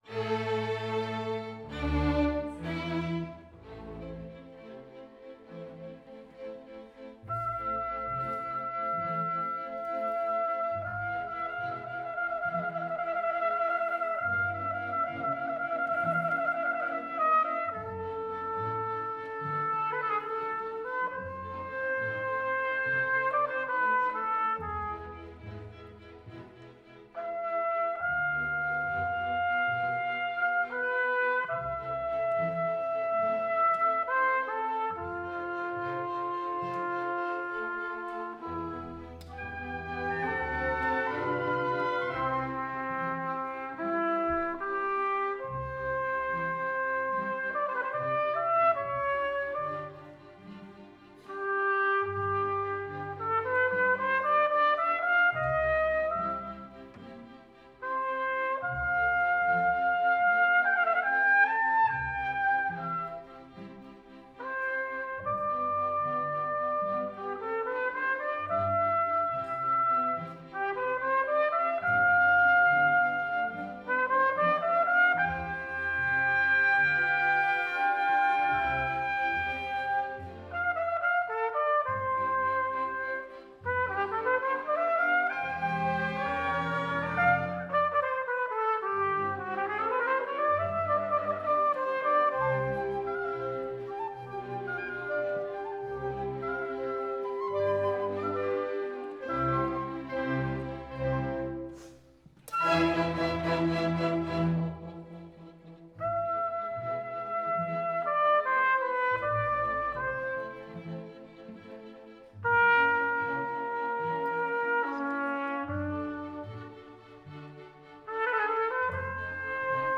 Trompete
Live-Mitschnitt: 12.10.2025, Dreieinigkeitskirche, Berlin - Neukölln
trompetenkonzert_2_3.wav